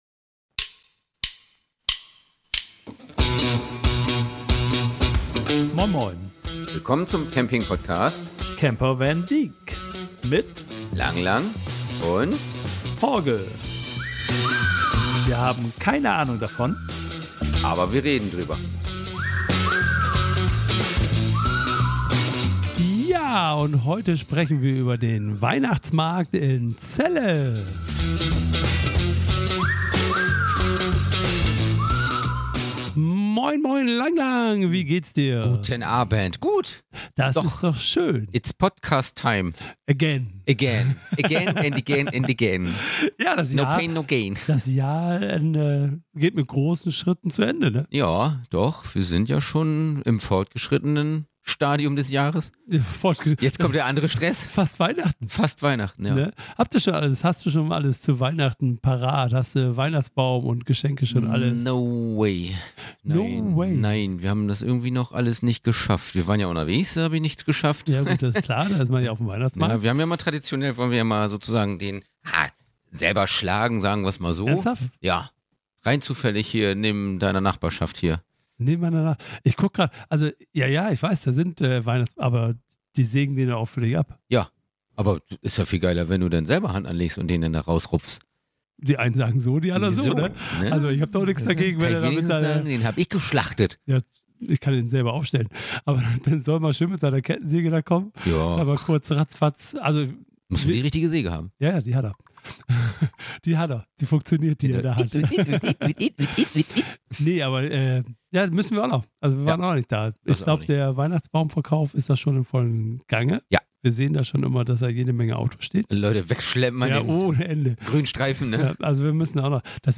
In dieser Folge nehmen wir Euch mit auf unseren Besuch auf dem Celler Weihnachtsmarkt. Über kleine Probleme mit einer Schranke. Jede Menge nette Menschen und lustige Gespräche und einfach viel Spaß auf einem kleinen, aber sehr gemütlichen Weihnachtsmarkt.